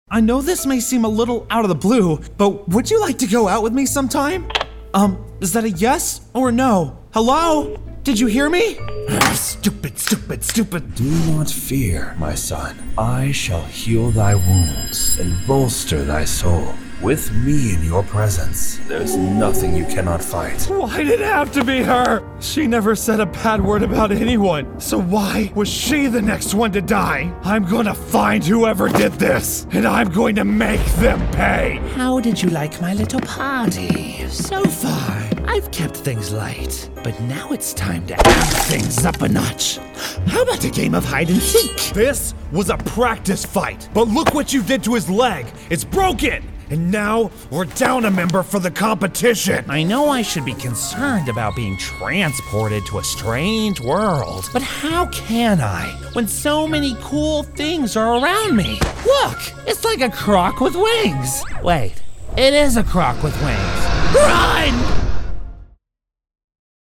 Llamativo, Versátil, Cálida, Amable, Empresarial